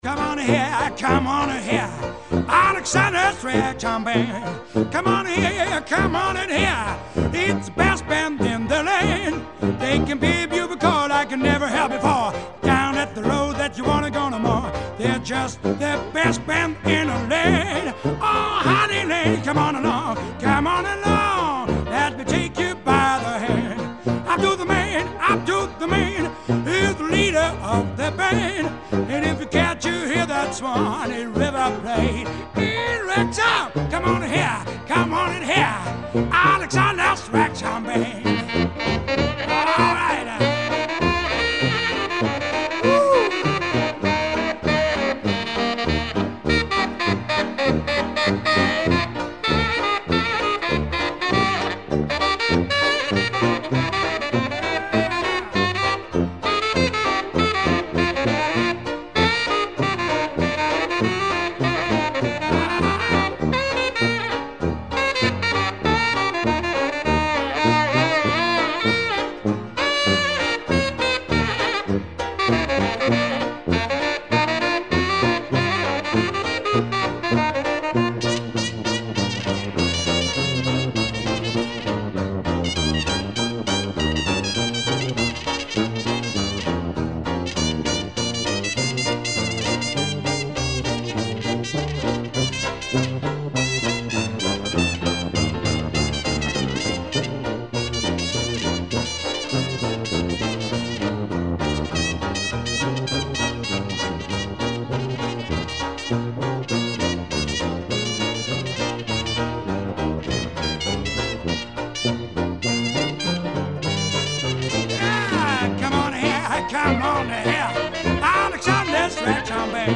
Festival d'Avignon 1999 - Musikanten und Schauspieler